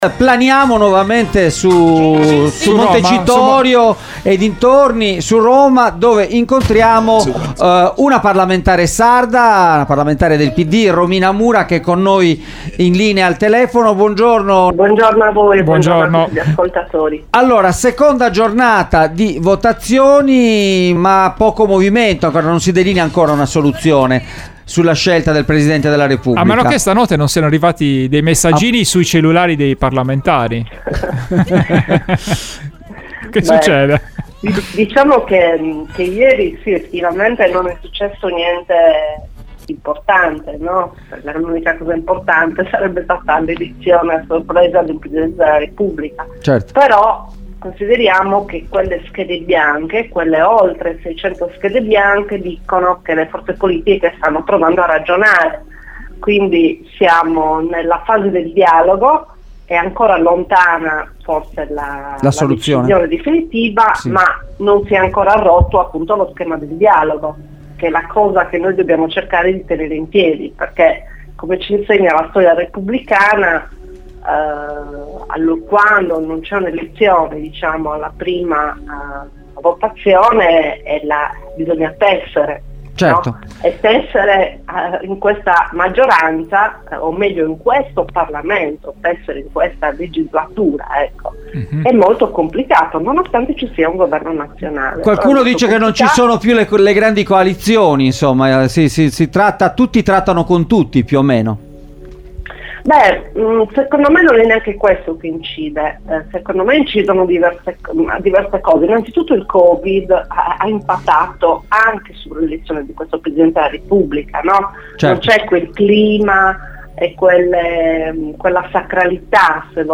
Romina Mura, parlamentare del PD, è intervenuta questa mattina ai microfoni di Extralive